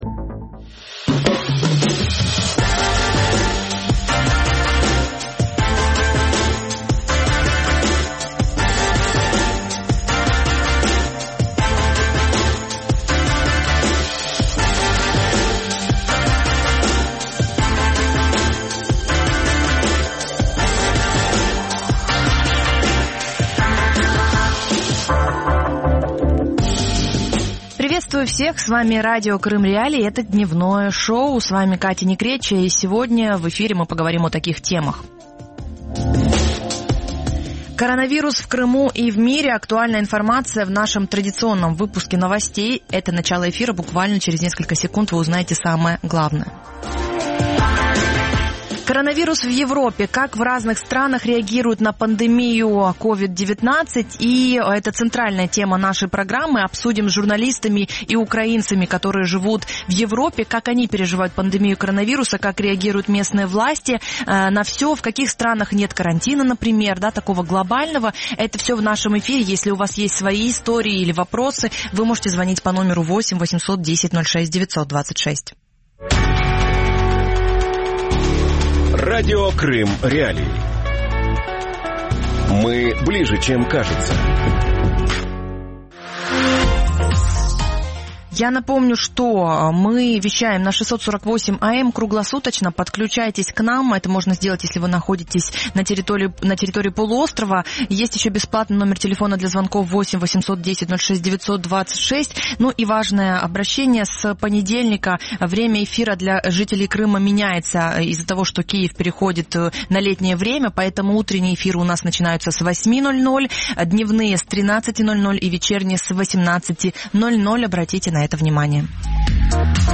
Коронавирус в Европе. Как в странах ЕС переживают пандемию | Дневное ток-шоу